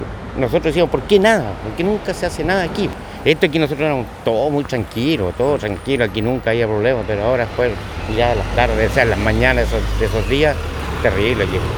Otro de los habitantes del sector explicó que, pese a las reiteradas denuncias, nadie hacía nada, hasta hoy.